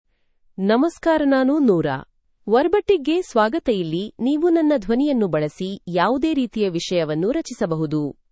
Nora — Female Kannada (India) AI Voice | TTS, Voice Cloning & Video | Verbatik AI
Nora is a female AI voice for Kannada (India).
Voice sample
Listen to Nora's female Kannada voice.
Female